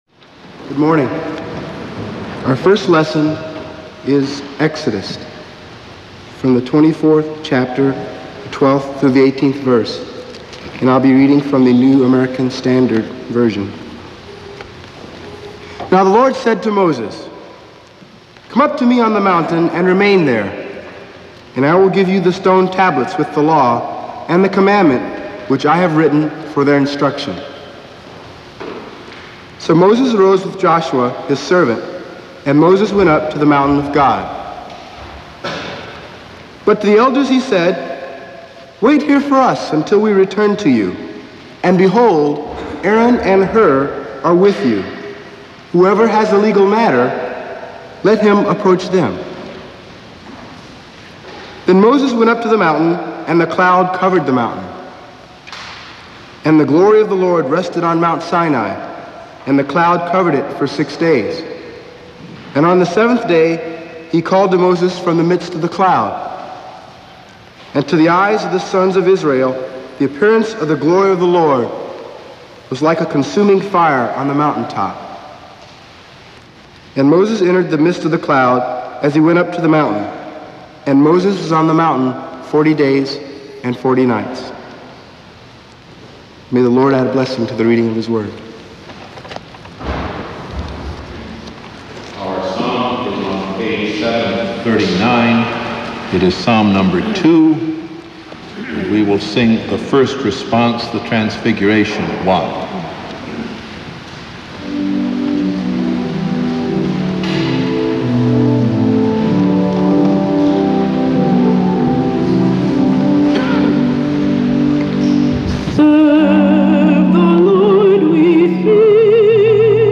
A Transfiguration Sunday sermon delivered by Rev. Dr. William Willimon at Marsh Chapel. Scripture readings: Exodus 24:12-18, Psalm 2, 2 Peter 1:16-21, and a Gospel reading.